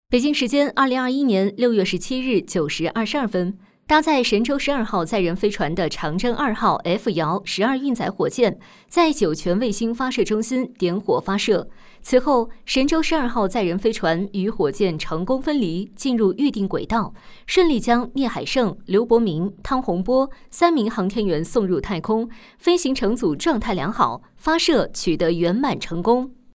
100%人工配，价格公道，配音业务欢迎联系：
C女116号
【新闻】时政新闻
【新闻】时政新闻.mp3